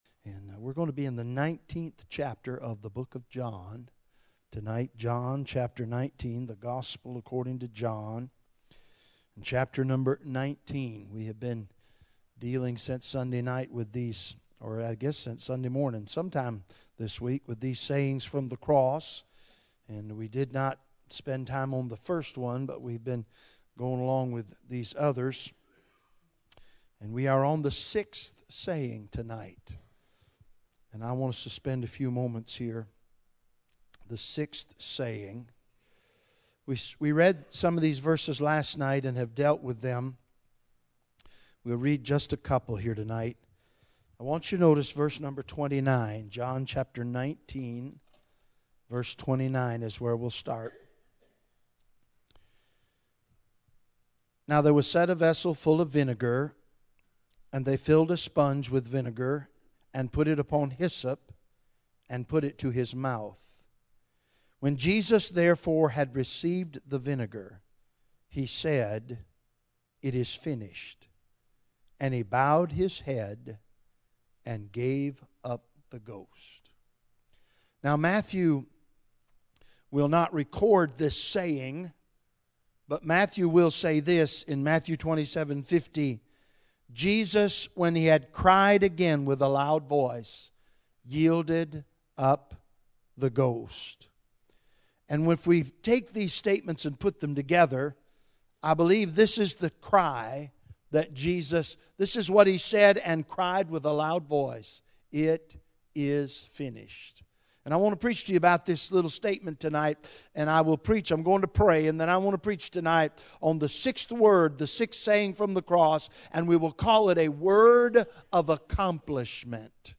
Bible Text: John 19:29 | Preacher